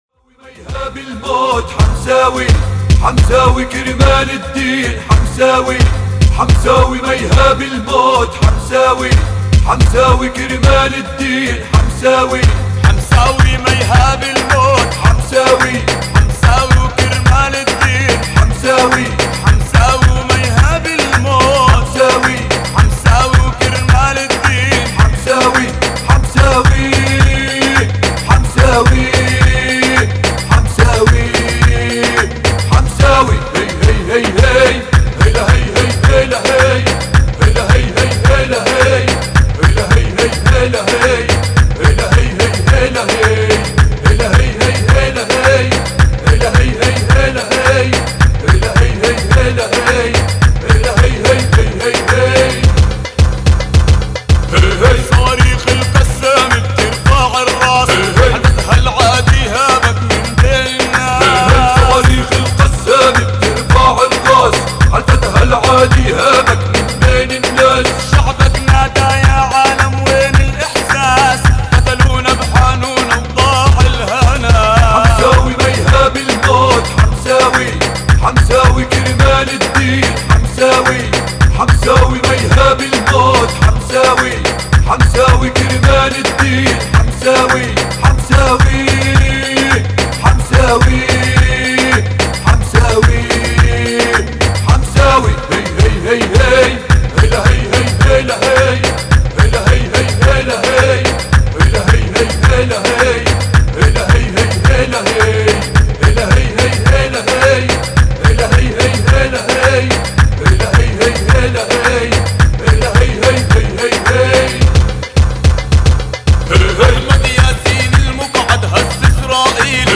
أناشيد فلسطينية... حمساوي